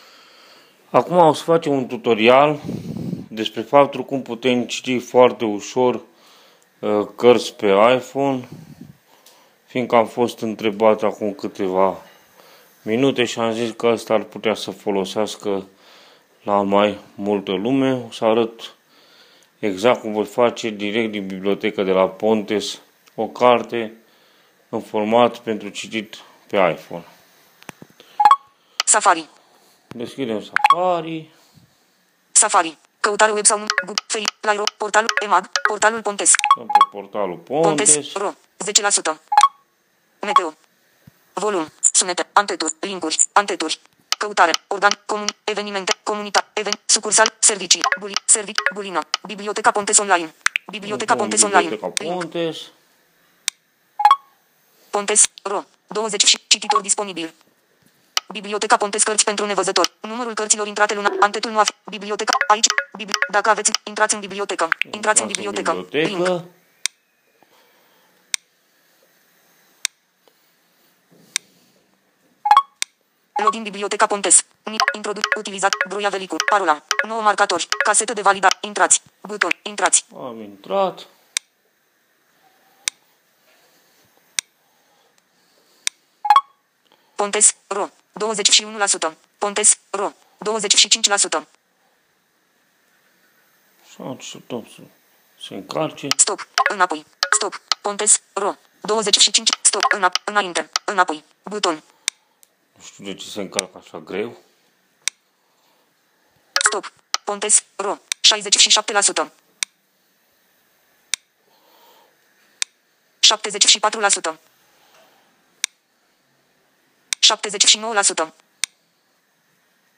Tutorial_05_-_Citire_carti_cu_iPhone.m4a